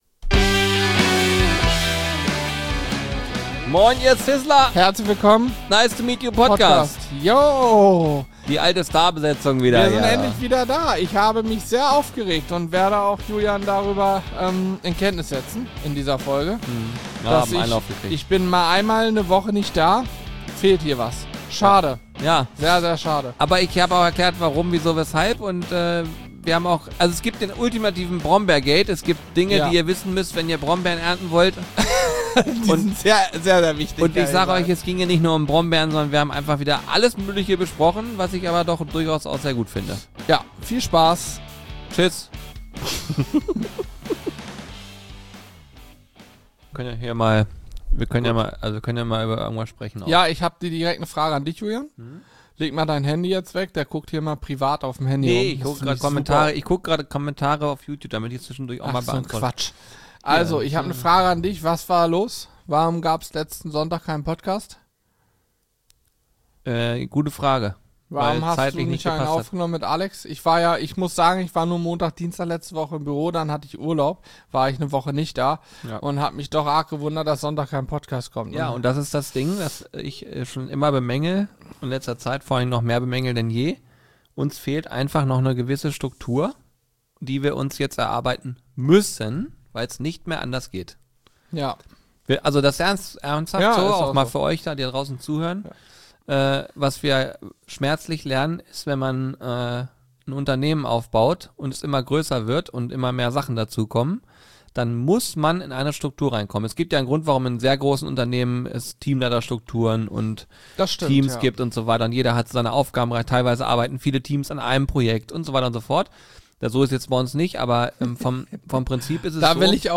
Es geht um erste Livestream-Erfahrungen, Food-Vorlieben, kreative Werbeideen und darum, wie sich Praktikum anfühlen kann wie Zuhause. Ein Talk mit viel Humor, echtem Interesse und ganz viel Hunger!